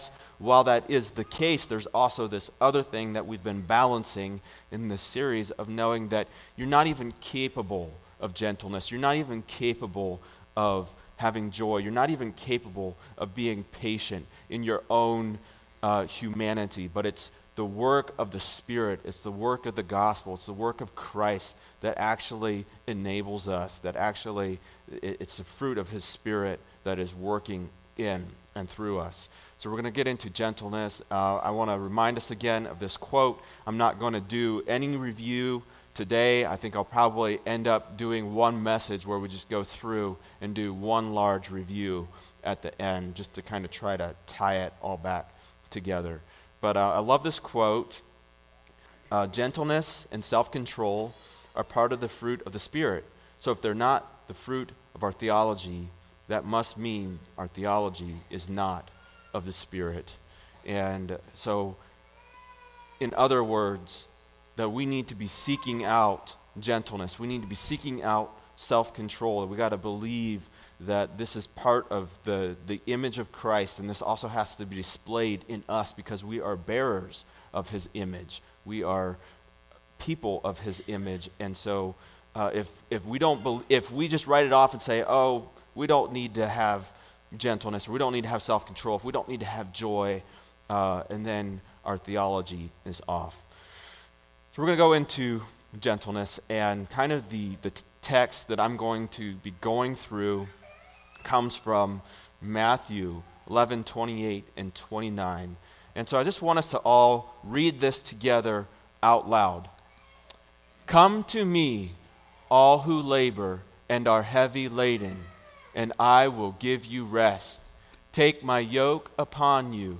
Series: Fruit of the Spirit Service Type: Sunday Message